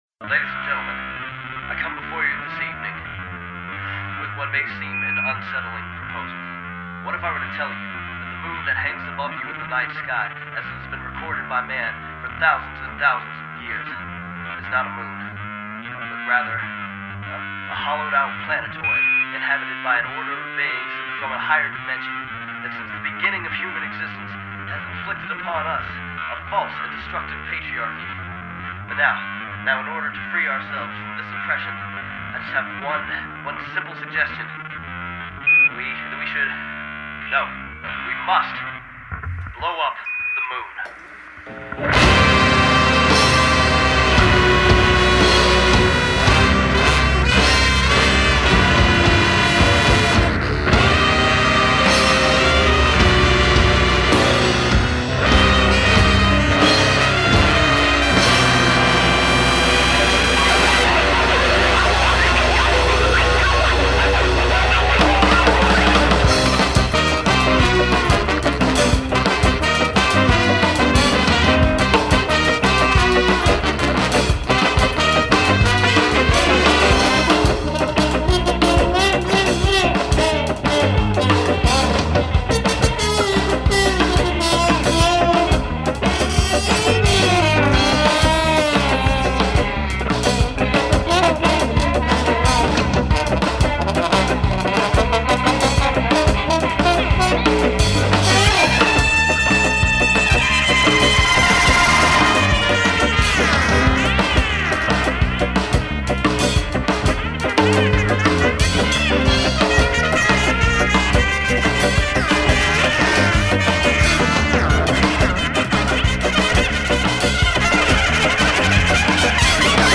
ska band